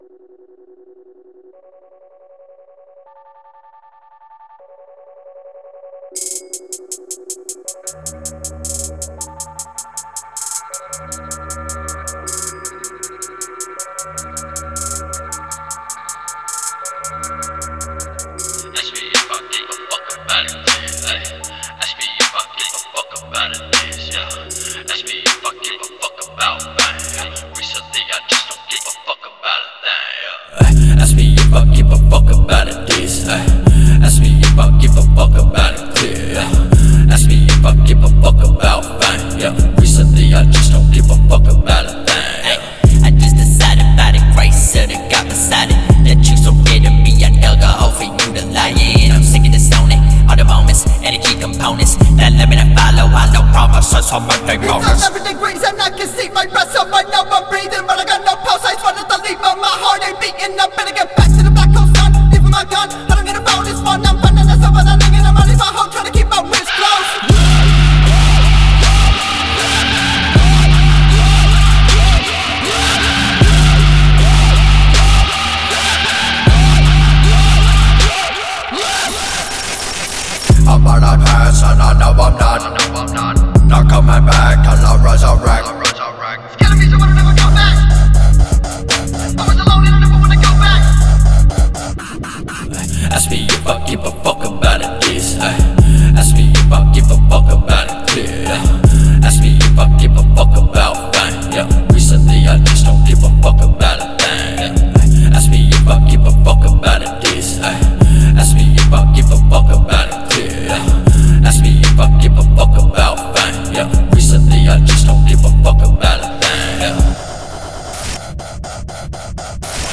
رپ پانک راک